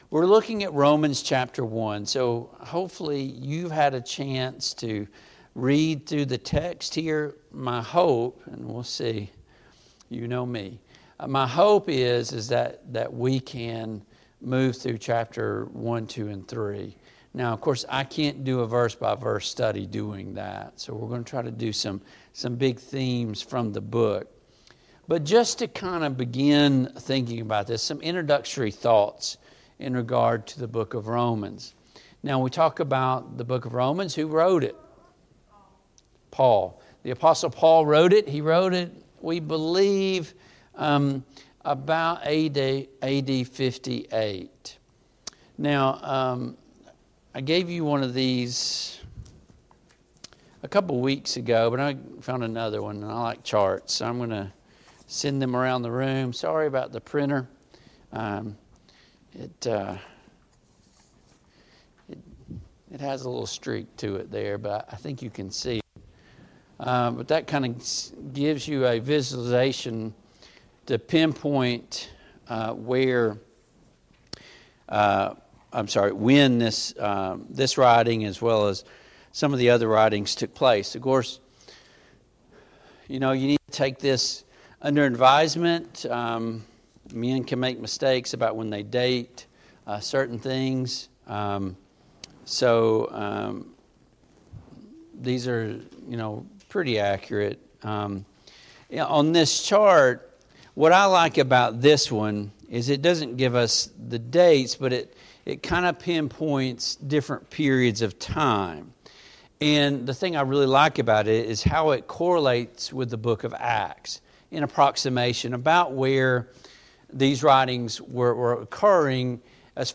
A Study of Romans Passage: Romans 1-3 Service Type: PM Worship Topics